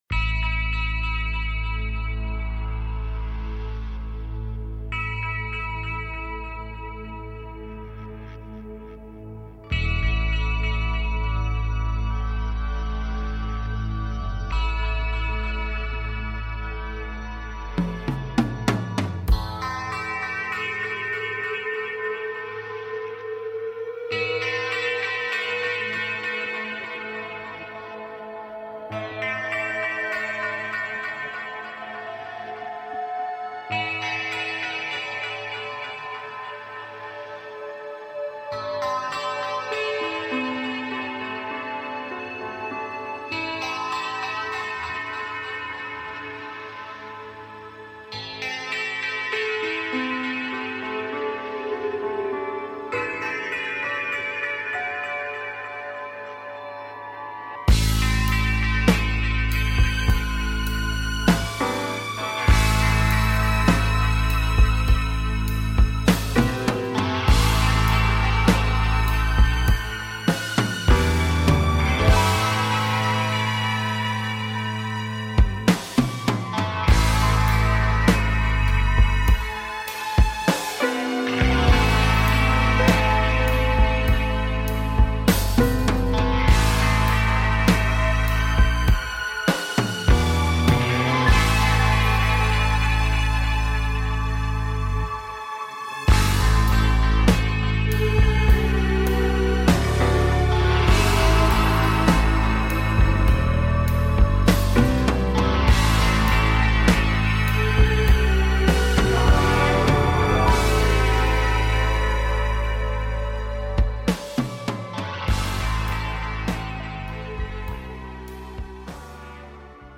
A show based on Timelines and manifesting Timelines while taking caller questions.